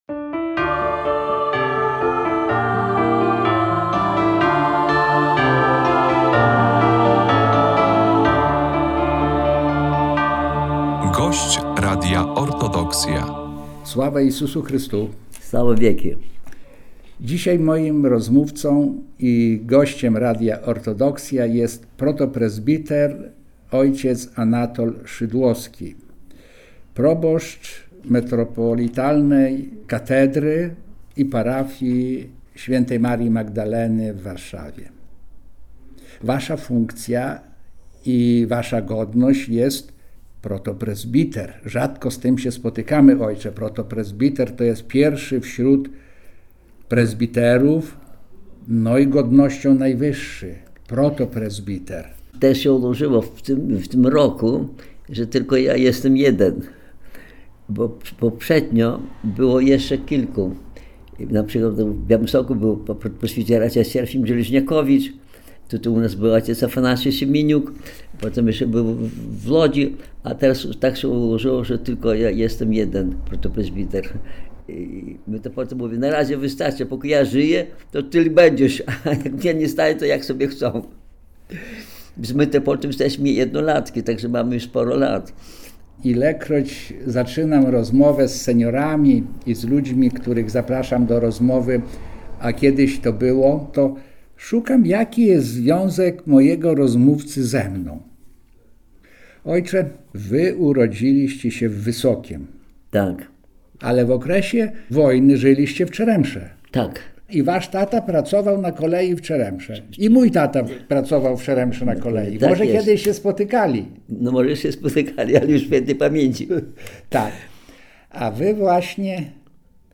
A kiedyś to było… rozmowy o życiu i Cerkwi – to cykl audycji radiowych z prawosławnymi duchownymi, którzy tworzyli historię Polskiego Autokefalicznego Kościoła Prawosławnego. W każdym odcinku programu zaproszeni goście opowiadają o swoich doświadczeniach związanych z posługą duszpasterską na przestrzeni ostatnich kilkudziesięciu lat.